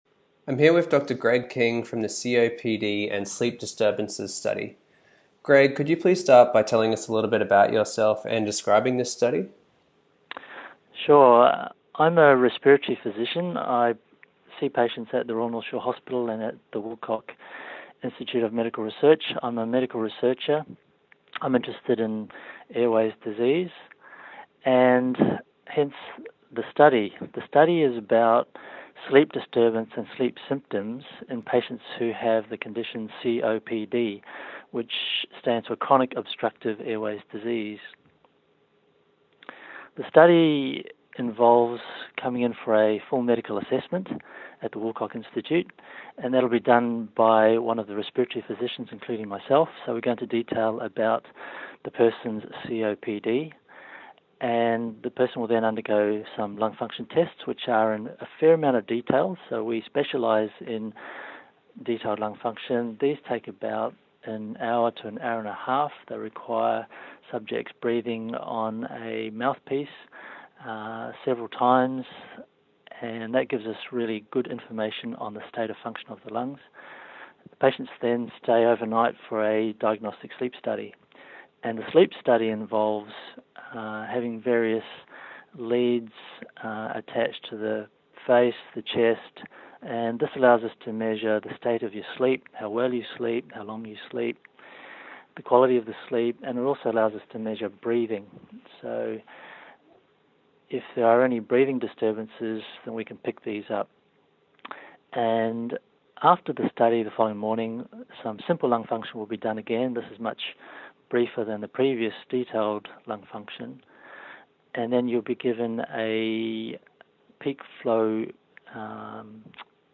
Researcher Interview